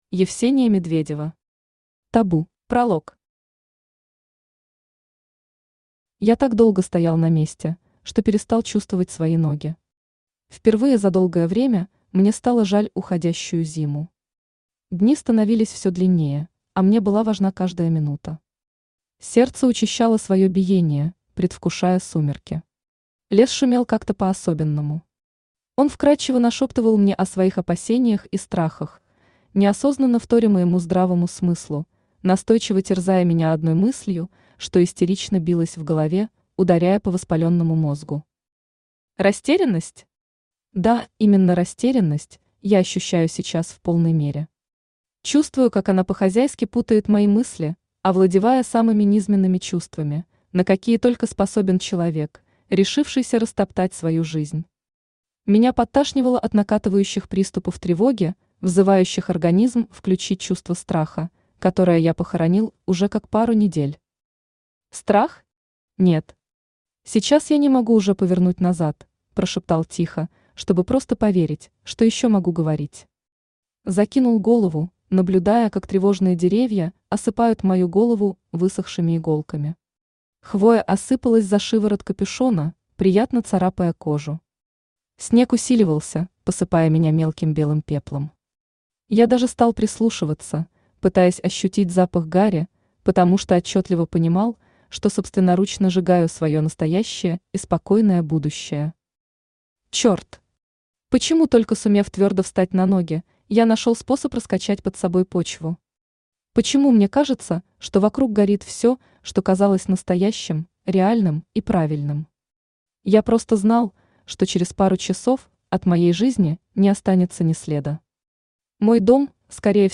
Аудиокнига Табу | Библиотека аудиокниг
Aудиокнига Табу Автор Евсения Медведева Читает аудиокнигу Авточтец ЛитРес.